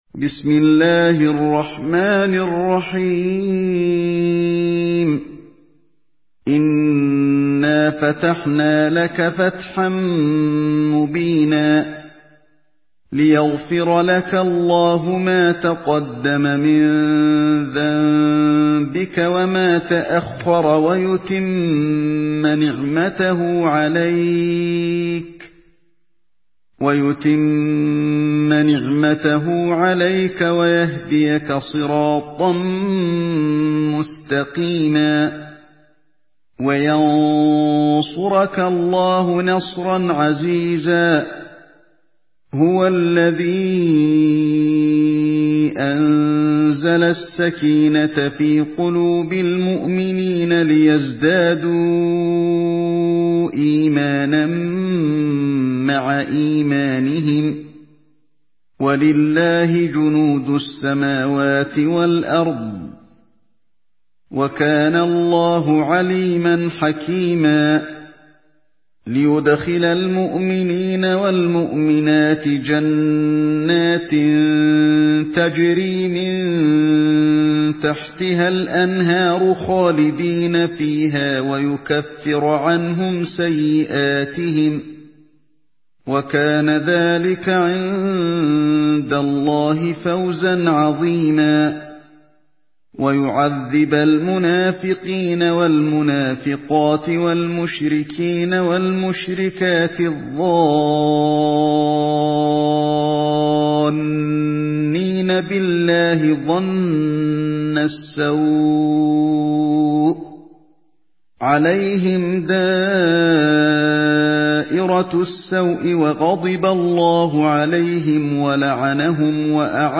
Surah Al-Fath MP3 by Ali Alhodaifi in Hafs An Asim narration.
Murattal